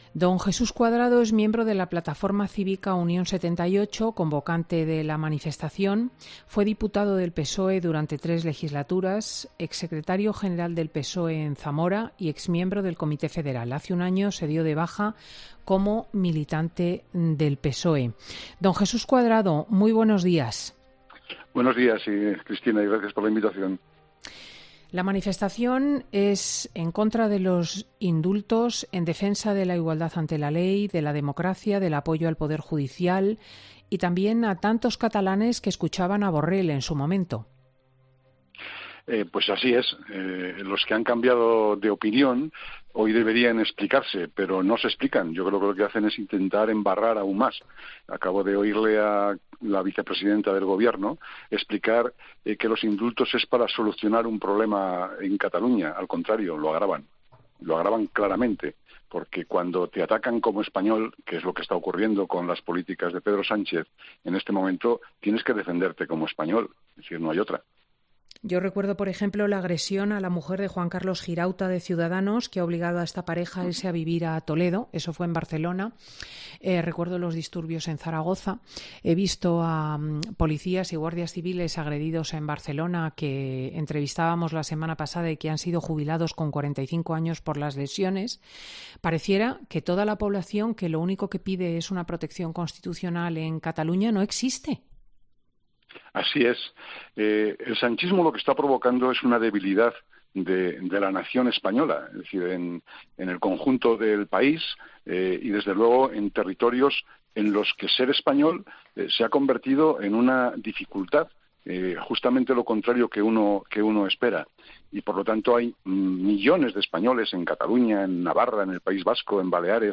El que fuera parlamentario socialista ha criticado en los micrófonos de Fin de Semana de COPE que “los que han cambiado de opinión hoy deberían explicarse, pero no lo hacen, embarran aún más”.